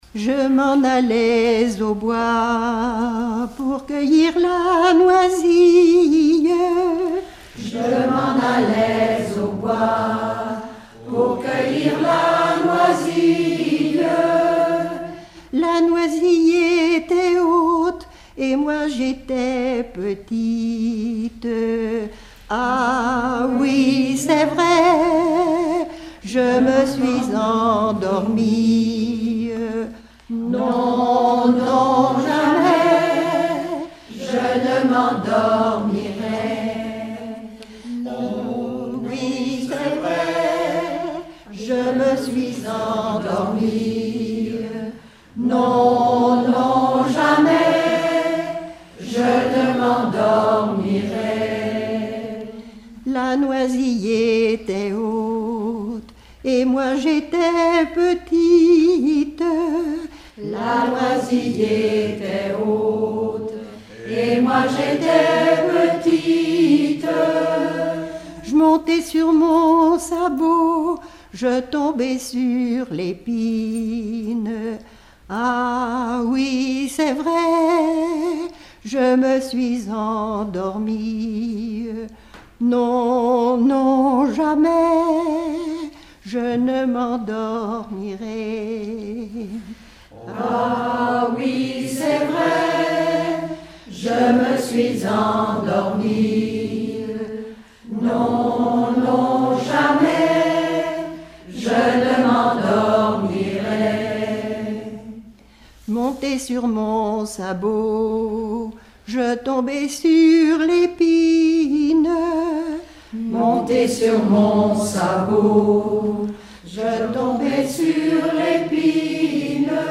Genre laisse
Regroupement de chanteurs du canton
Pièce musicale inédite